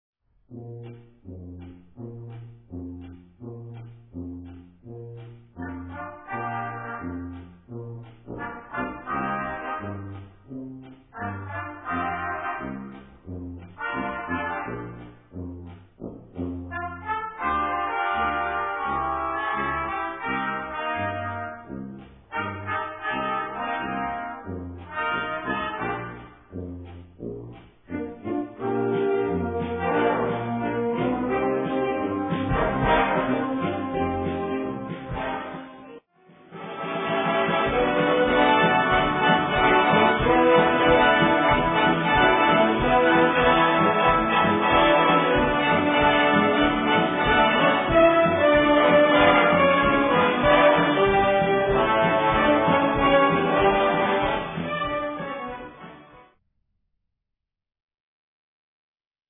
Gattung: Swing
Besetzung: Blasorchester